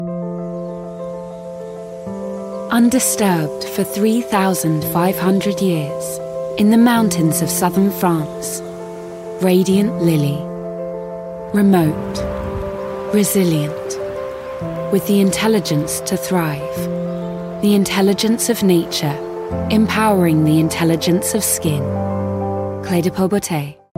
20/30's Neutral/RP,
Compelling/Cool/Assured